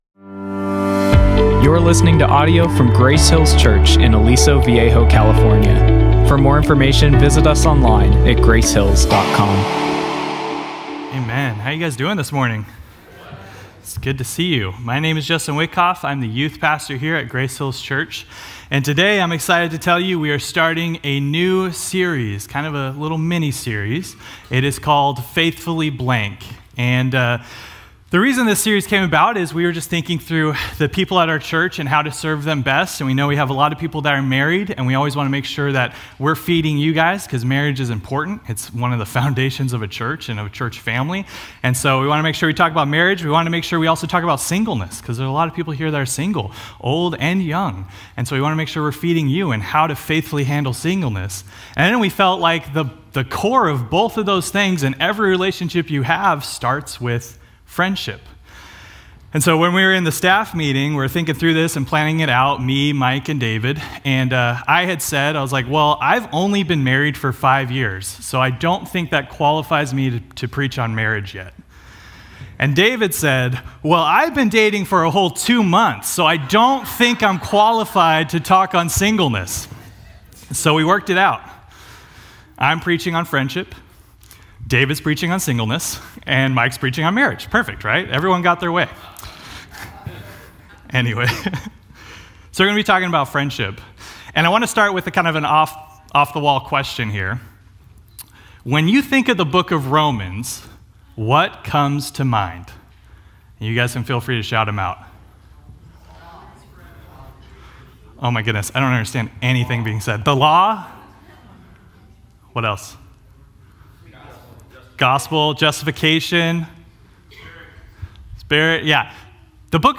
A message from the series "Faithfully...."